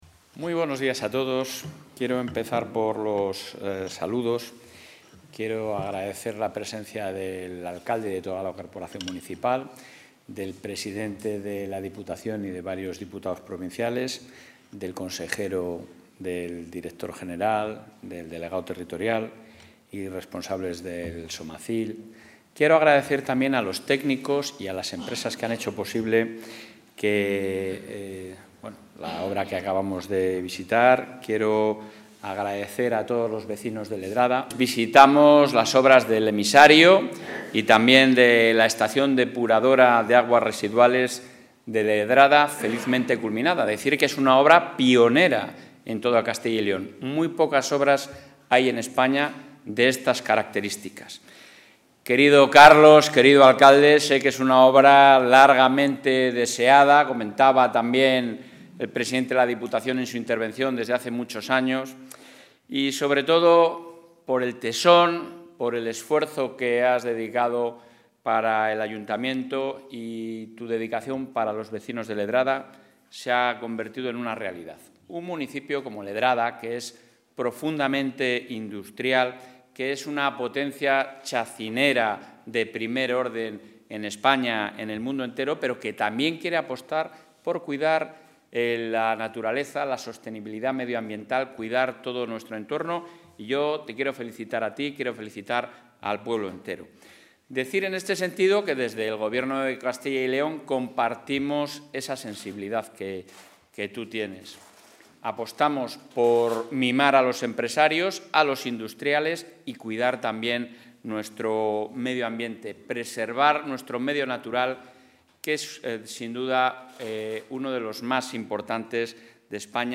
Intervención del presidente de la Junta.
El presidente de la Junta de Castilla y León, Alfonso Fernández Mañueco, ha visitado hoy la obra finalizada de la estación depuradora de aguas residuales del municipio salmantino de Ledrada, que ha contado con una inversión de 3,7 millones de euros financiados al 80 % por el Ejecutivo autonómico con recursos propios y fondos FEDER, y al 20 % por el Ayuntamiento.